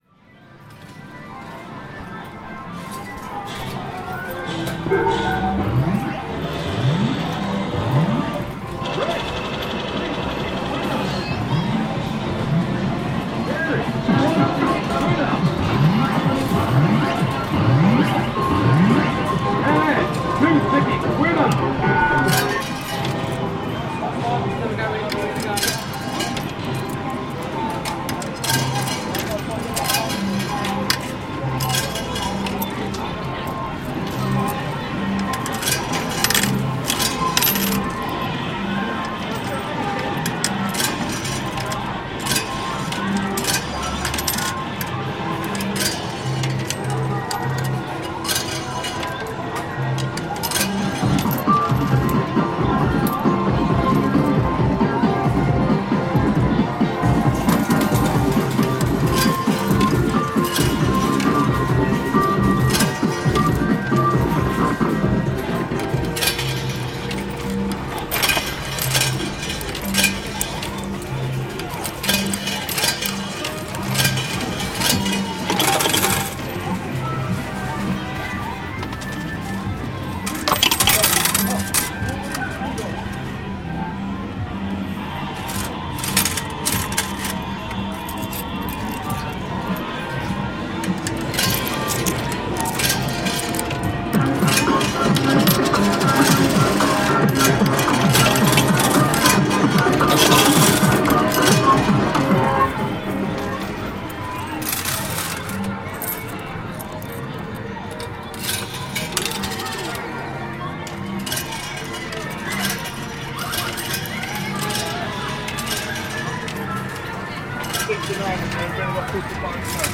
Winning on the 2p machines in Flamingo Arcade.